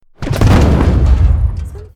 collision avec le vaisseau.mp3